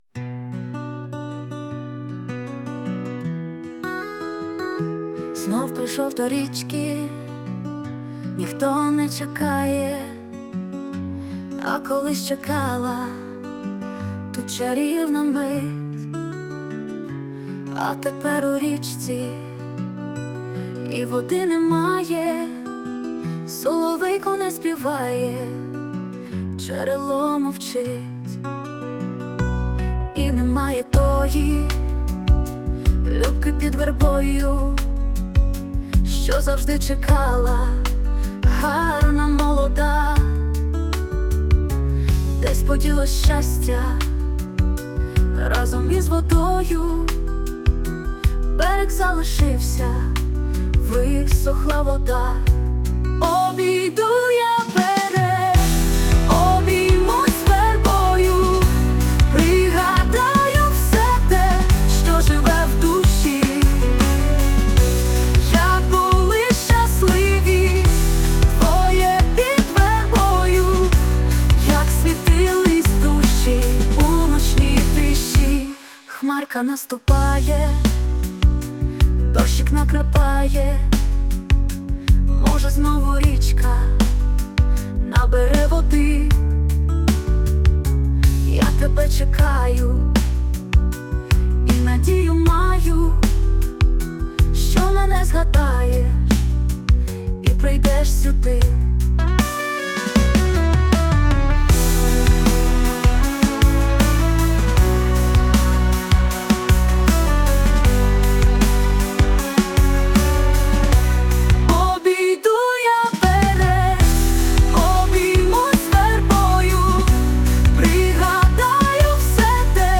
СТИЛЬОВІ ЖАНРИ: Ліричний
Зворушливо-чуттєві слова і душевна пісня.
мелодійно і ємоційно, чудова пісня про щирі почуття give_rose 12